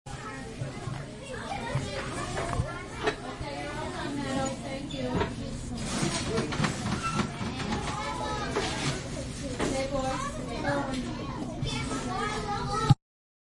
Download Classroom sound effect for free.
Classroom